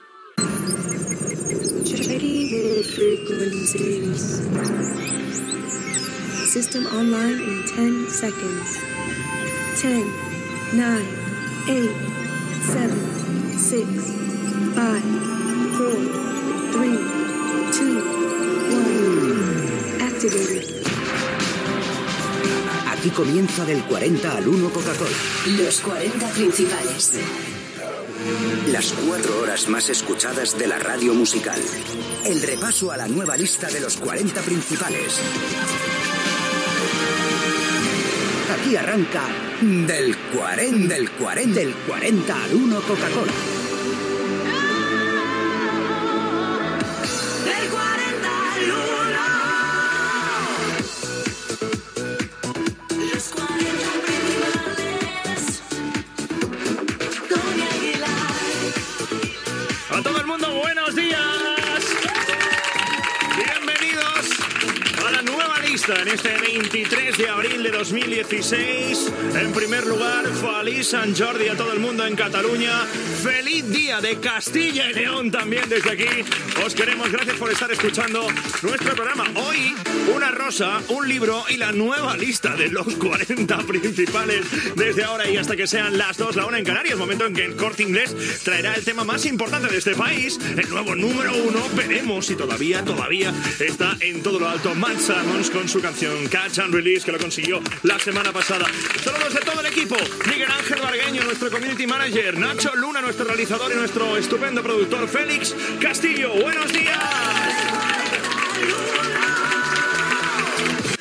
Careta del programa i salutació en la Diada de Sant Jordi, esment a l'últim tema que ocaupava el número 1 i equip
Musical
FM